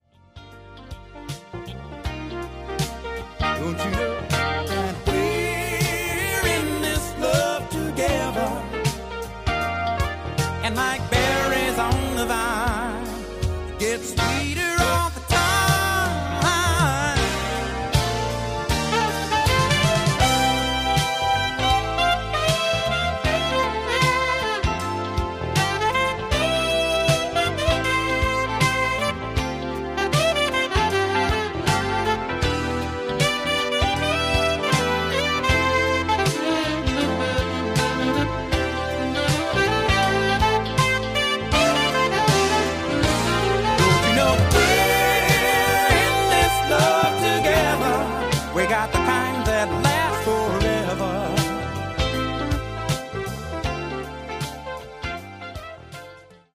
My alto sax solo